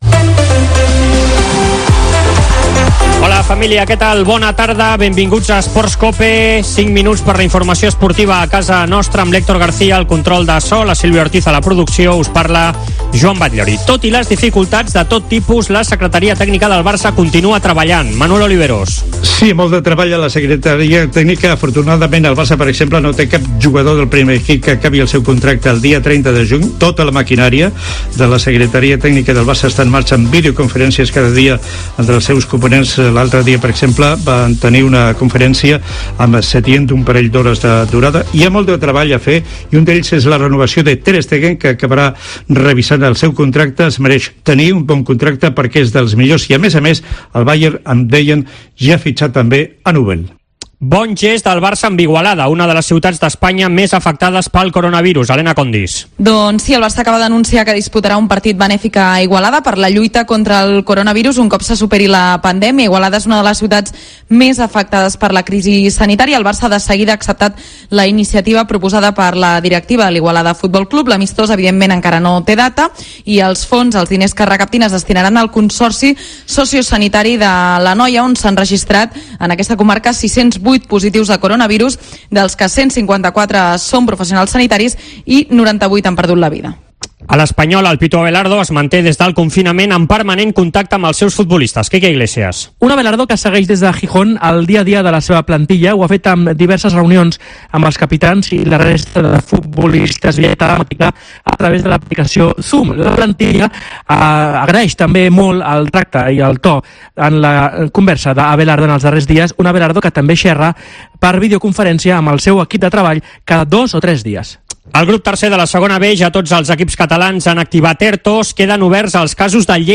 tot l'equip treballant des de casa per portar-vos l'actualitat esportiva més propera.